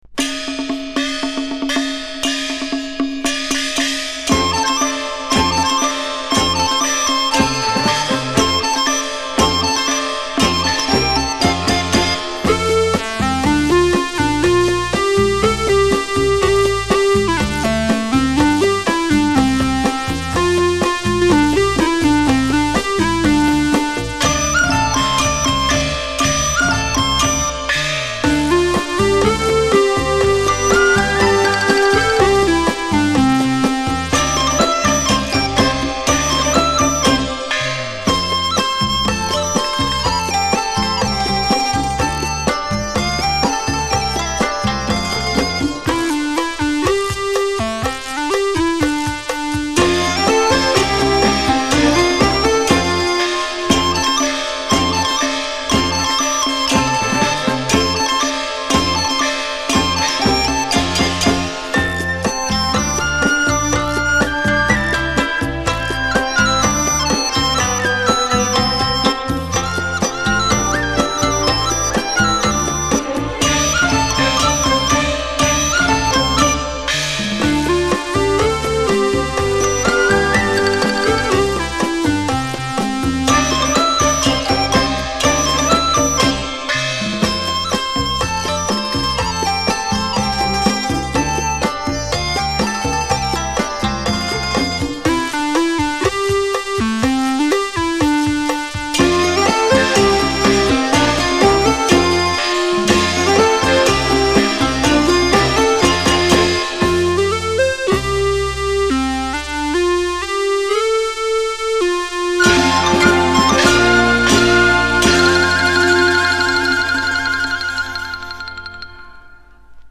媒体介质：LP→WAV+CUE 类 型：纯音乐